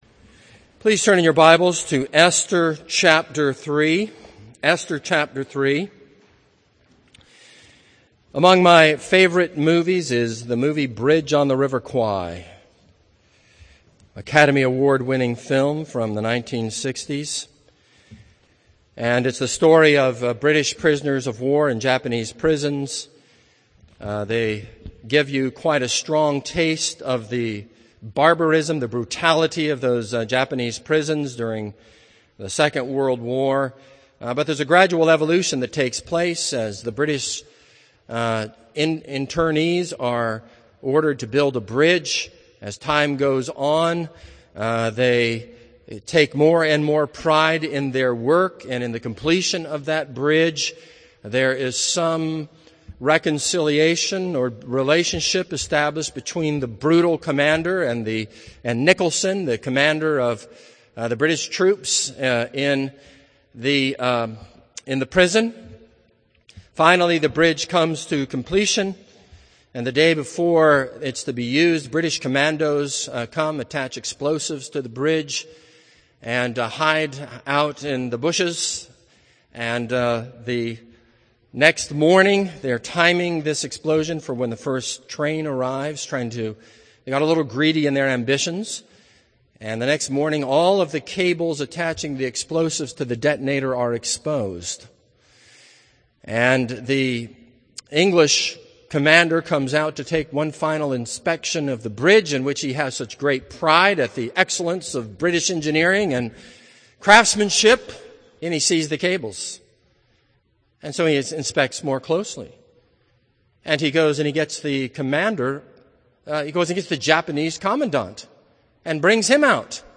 This is a sermon on Esther 3.